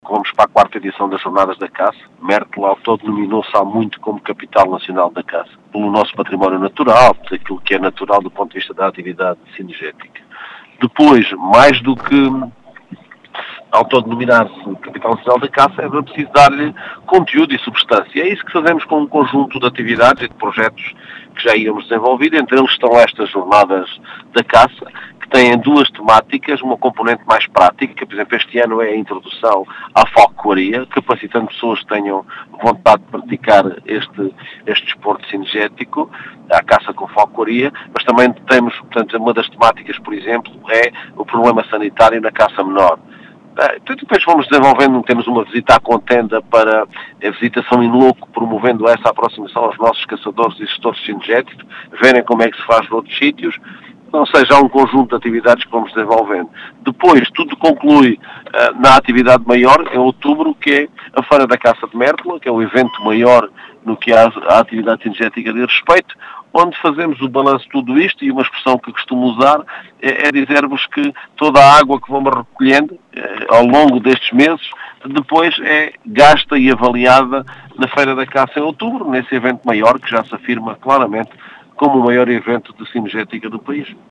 As explicações são do presidente da Câmara Municipal de Mértola, Mário Tomé, que deixa algumas novidades nestas jornadas.